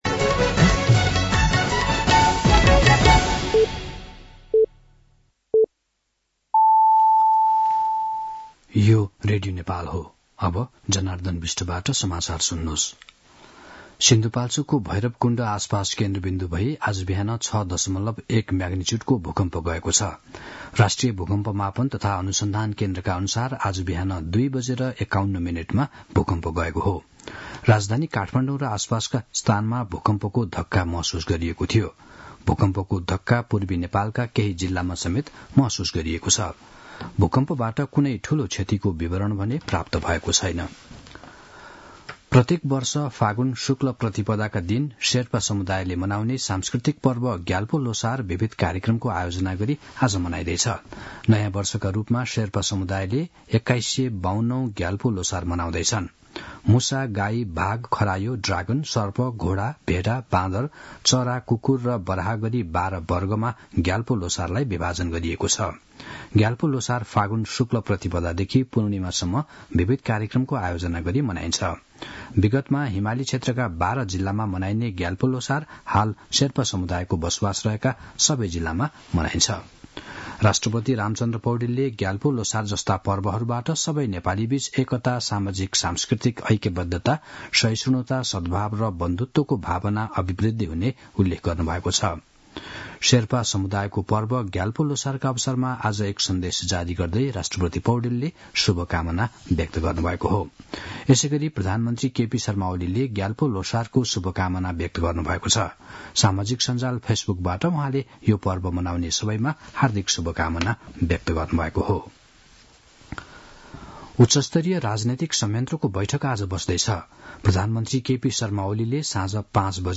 मध्यान्ह १२ बजेको नेपाली समाचार : १७ फागुन , २०८१